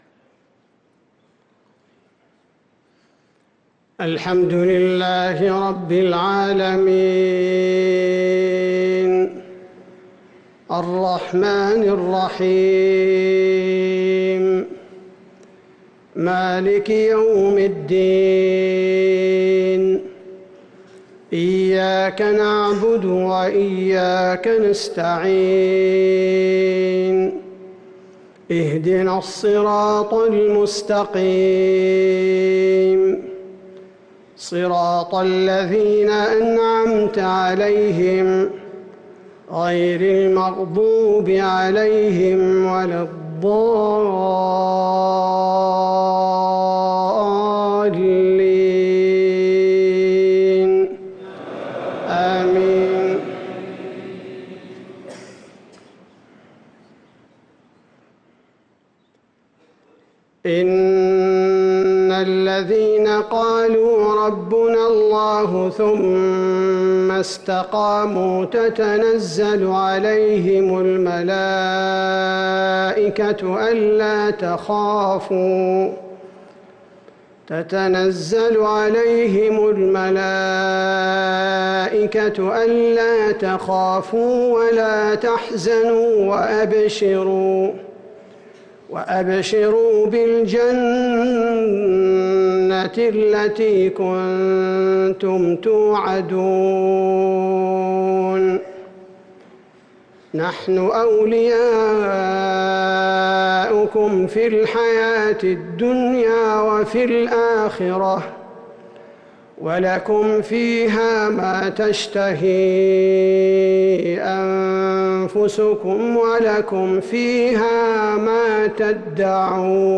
صلاة المغرب للقارئ عبدالباري الثبيتي 10 ربيع الأول 1445 هـ
تِلَاوَات الْحَرَمَيْن .